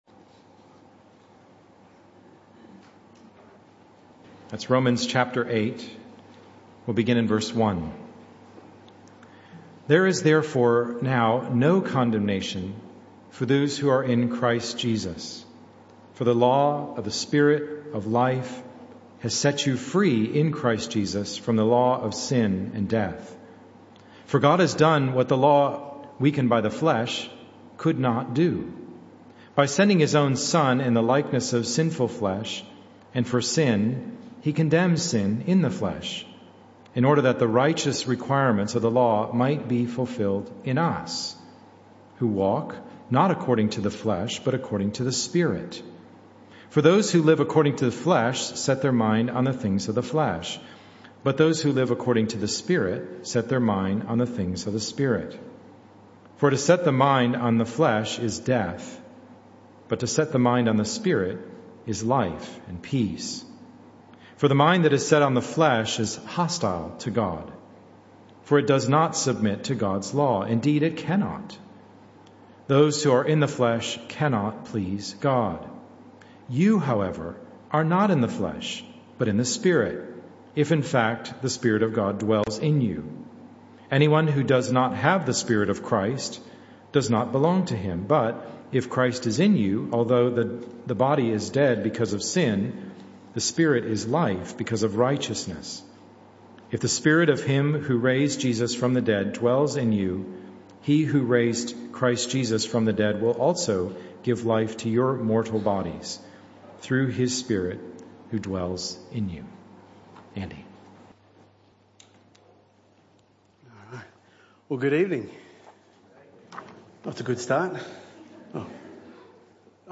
This talk was a one-off that took place in the PM Service.
Service Type: Evening Service